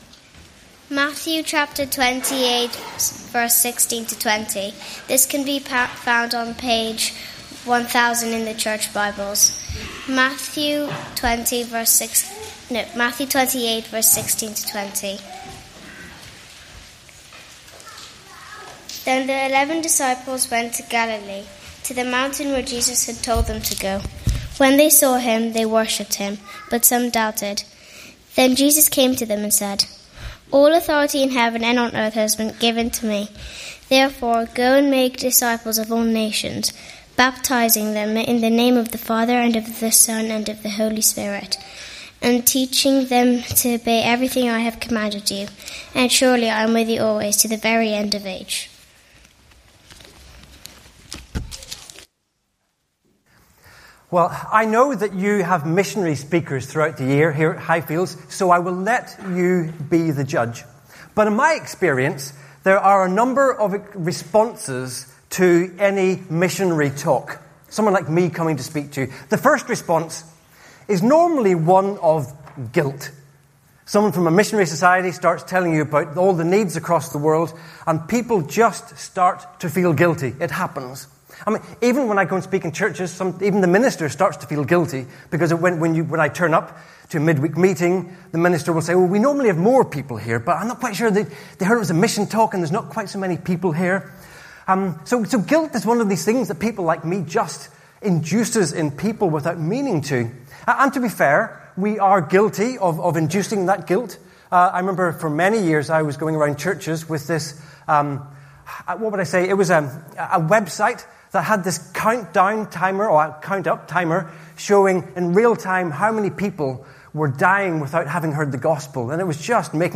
Morning Service
Sermon Title: World Focus Matthew 28:16-20 (Communion Service)